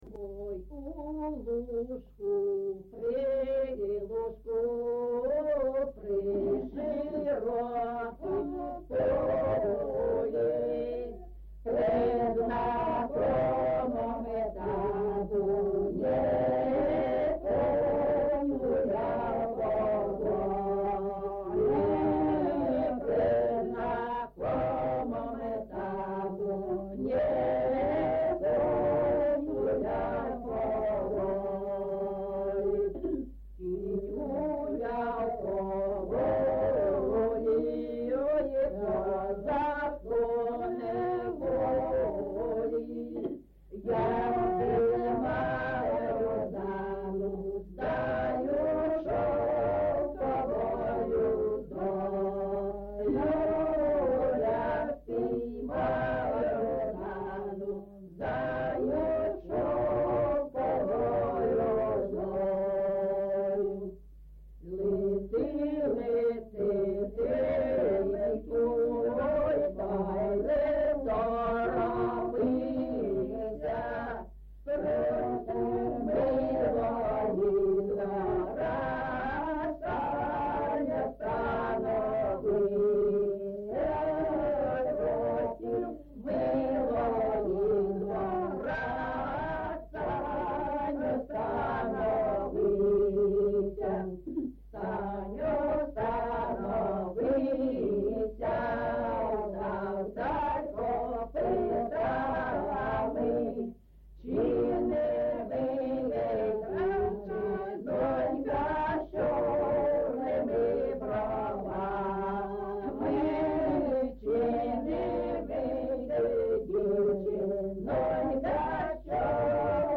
GenrePersonal and Family Life, Cossack
Recording locationHalytsynivka, Pokrovskyi district, Donetsk obl., Ukraine, Sloboda Ukraine